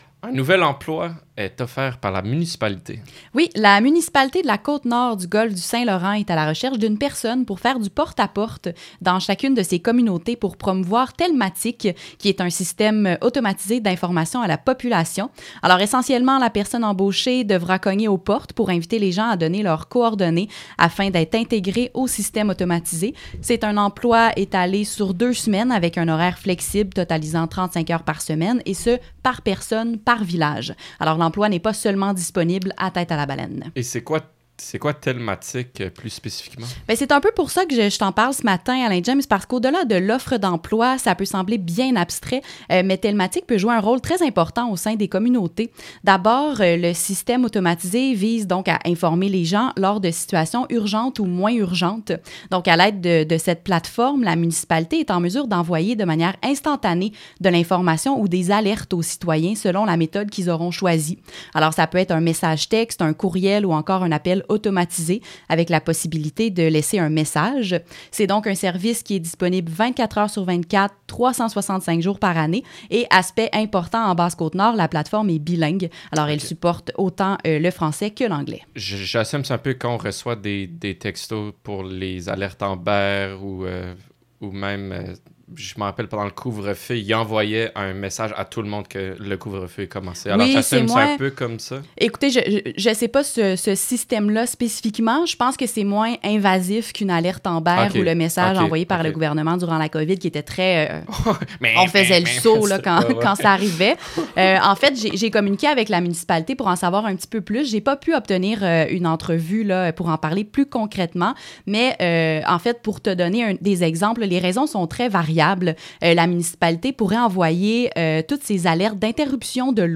écoutez la converse entre la journaliste